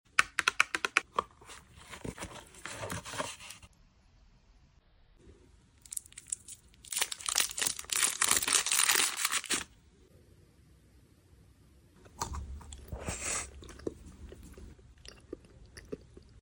Mocha / Coffee / Chocolate / ASMR Eating Sounds